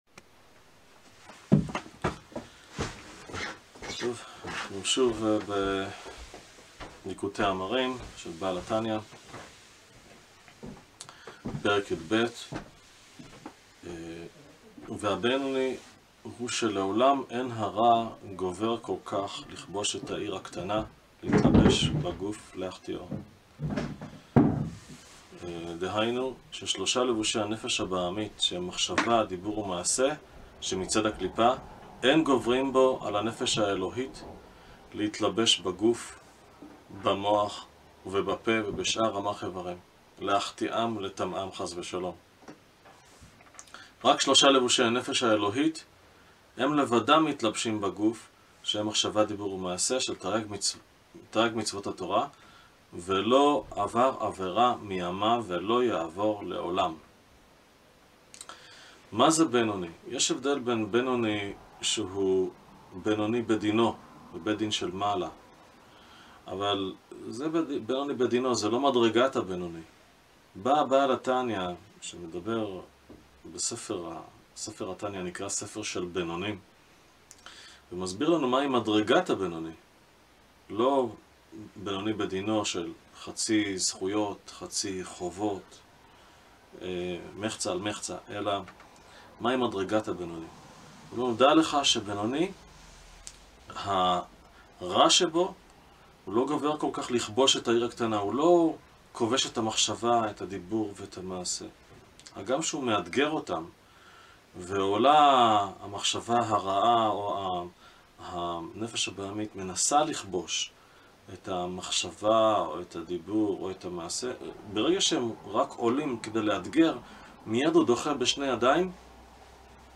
שיעור בתניא פרק יב'